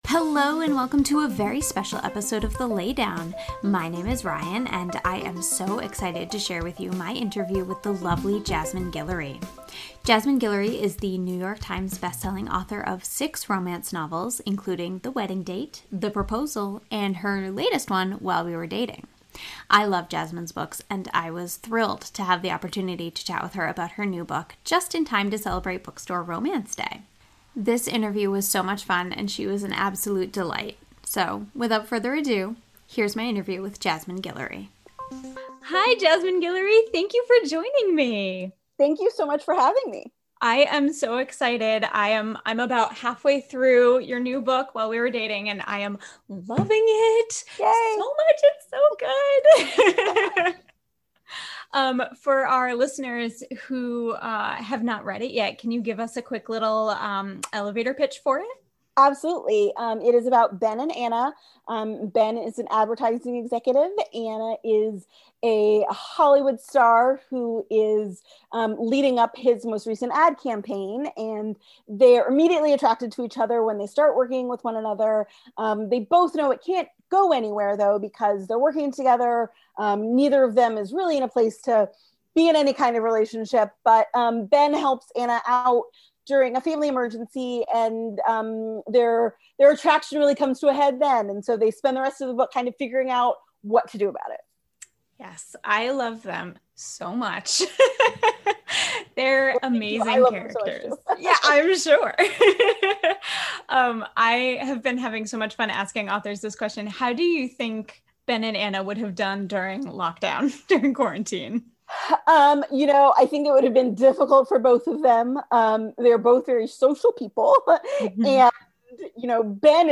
Jasmine Guillory Interview!